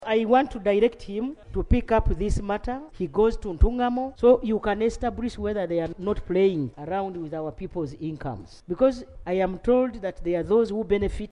AUDIO: Premier Robinah Nabbanja